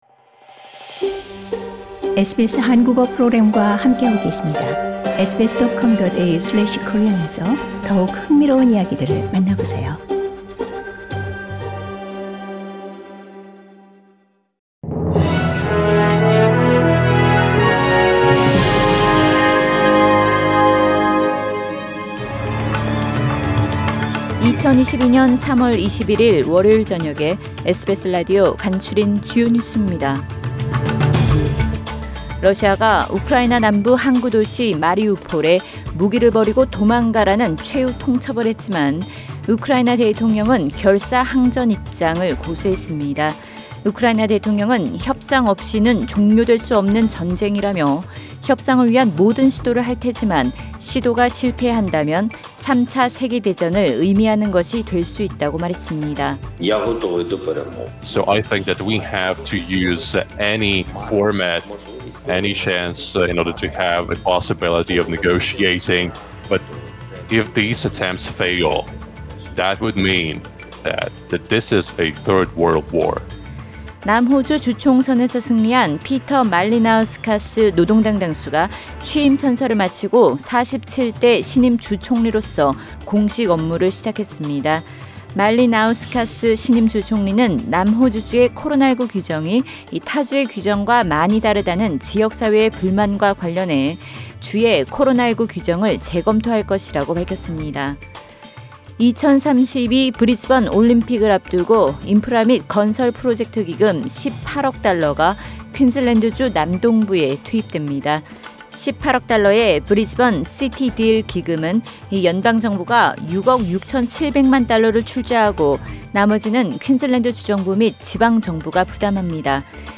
SBS News Outlines…2022년 3월 21일 저녁 주요 뉴스